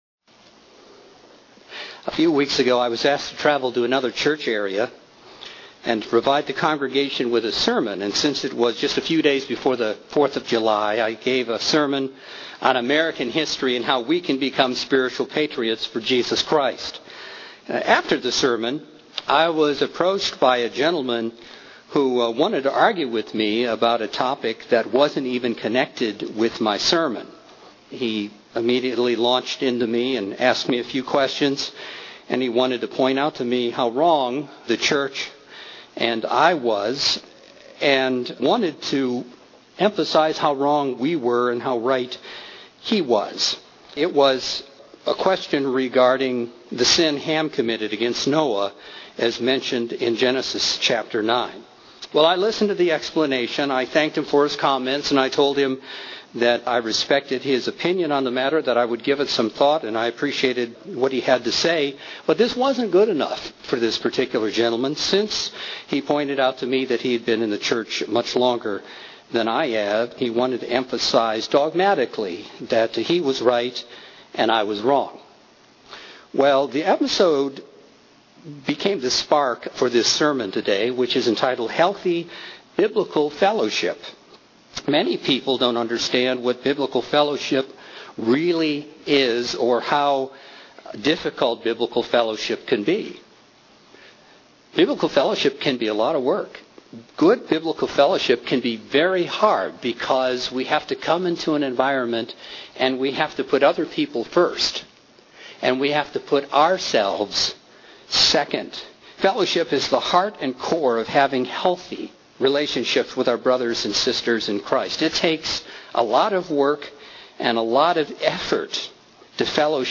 That episode gave me the spark for this sermon today.